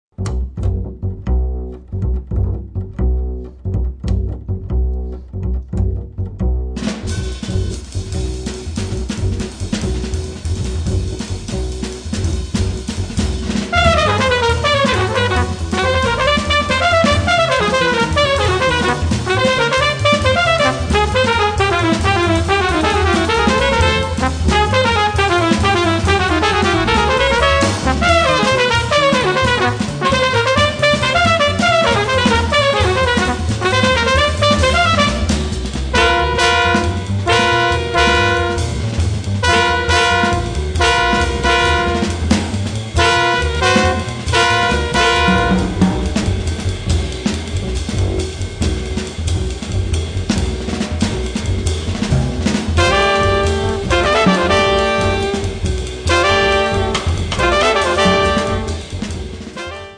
drum
double bass
alto sax and clarinet
trumphet, flugelhorn
trombone